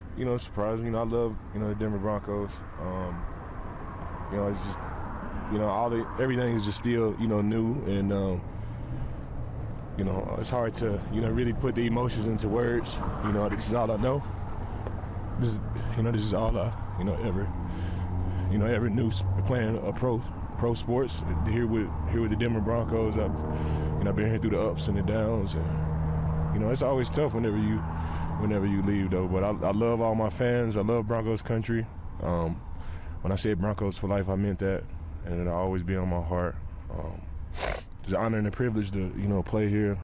He expressed how much he cares about the Broncos and how tough the move is for him. “This is all I know,” Miller said with a shaky voice.